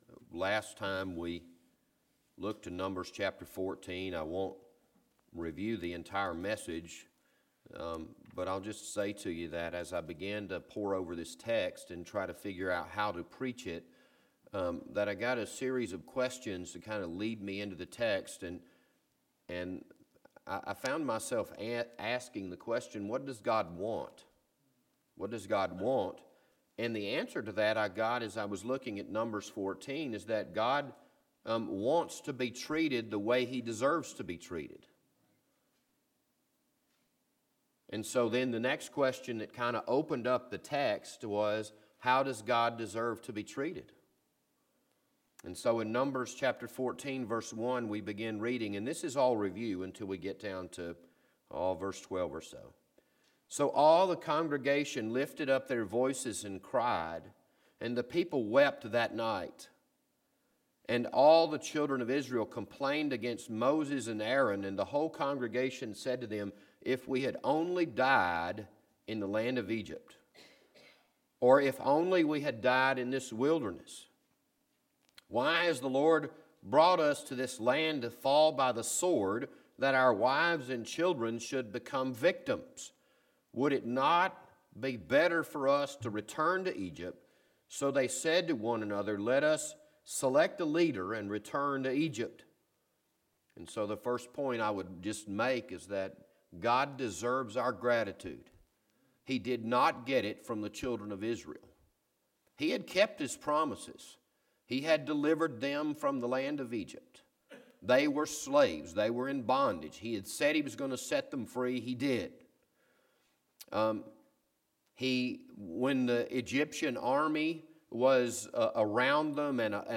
This Sunday morning sermon was recorded on January 14th, 2024.